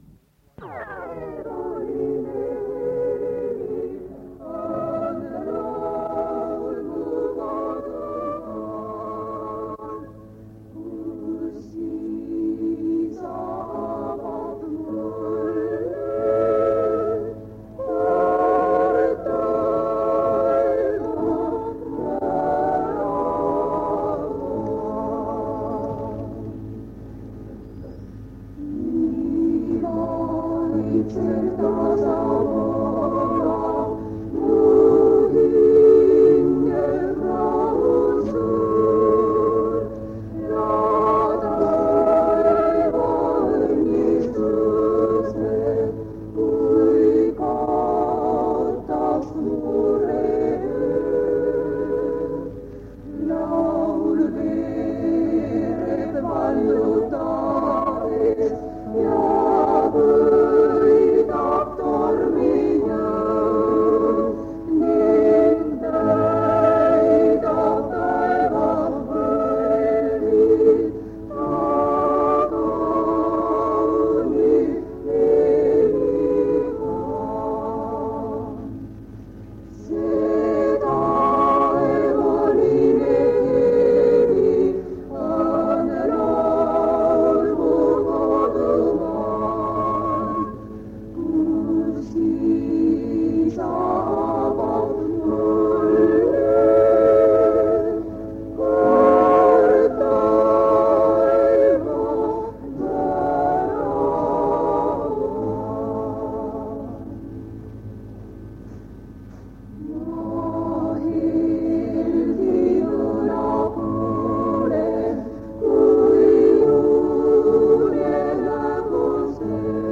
Jutlus vanalt lintmaki lindilt 1978 aastast.